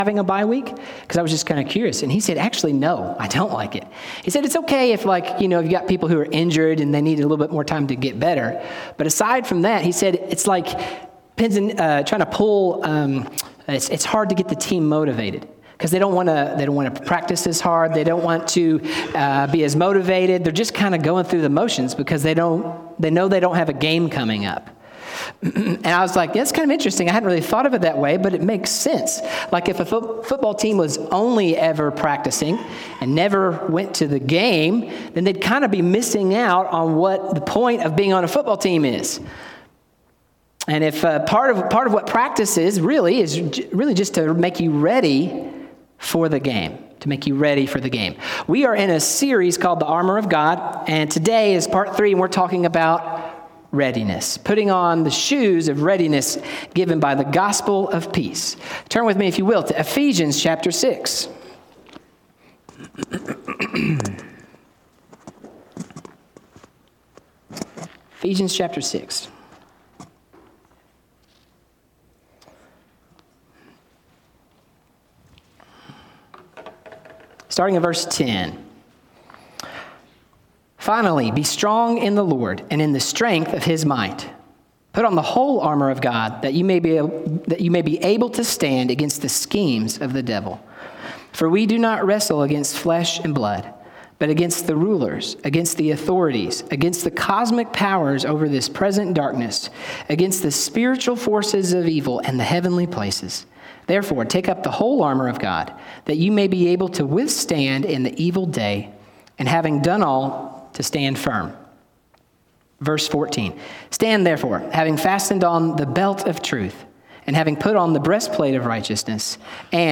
Sermons | Robertsville Baptist Church